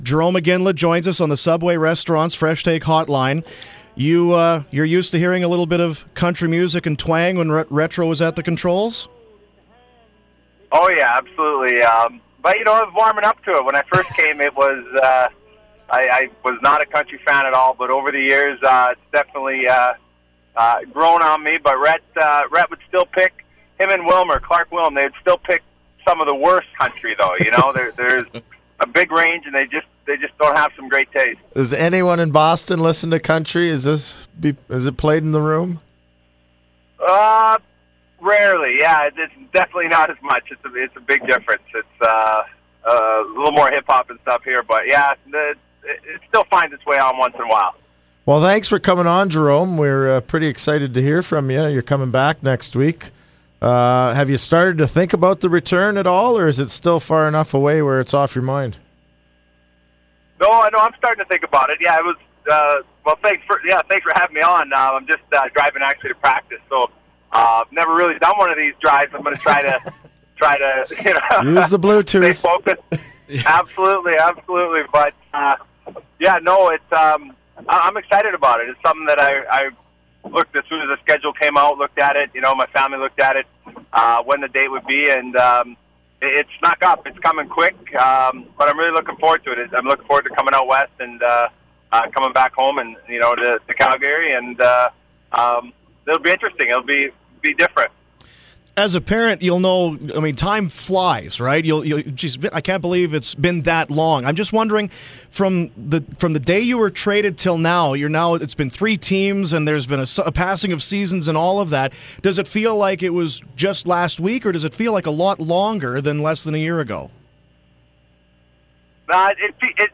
It was nice to just hear him laugh